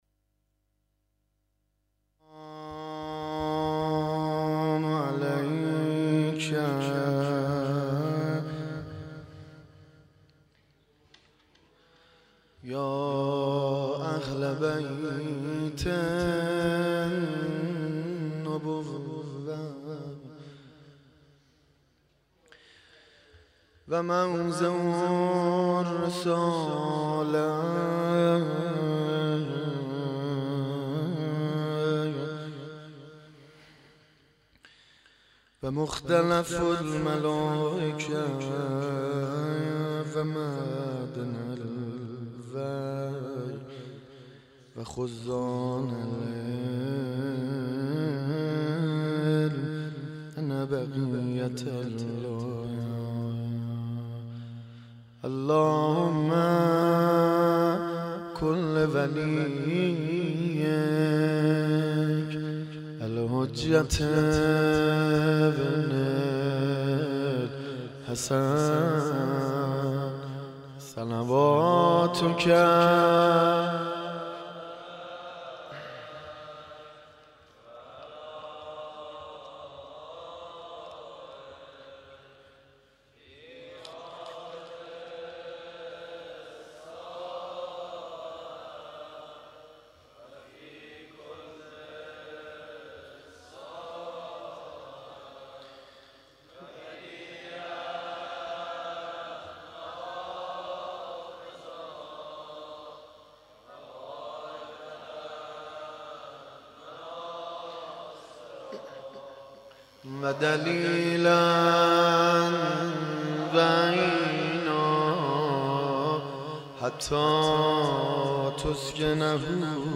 هیئت عشاق الرضا(علیه السلام) اسلامشهر
مداح